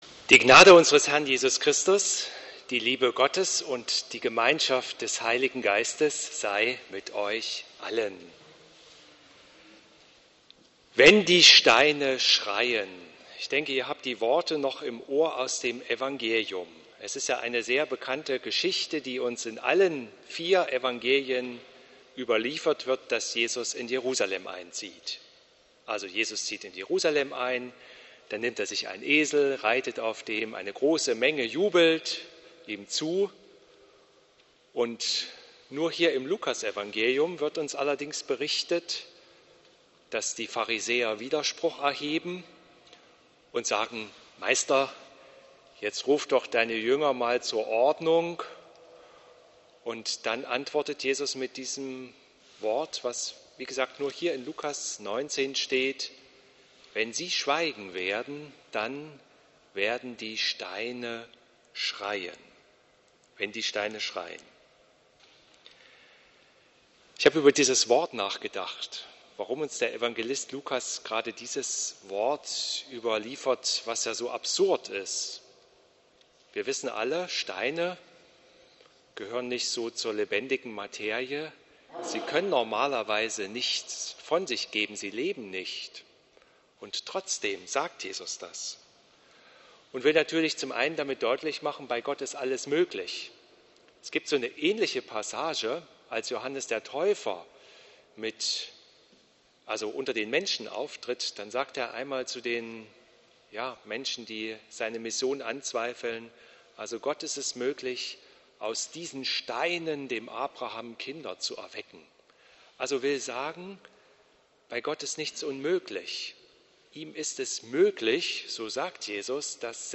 Lobpreis Archive - Kloster Volkenroda